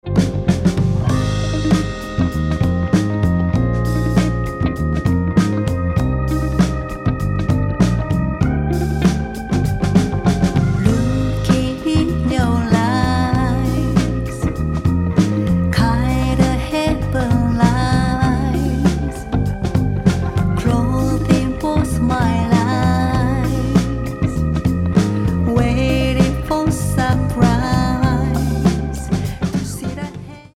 円熟のヴォーカルが描く、ソウル・ジャズ。
Vocal/Chorus
Drums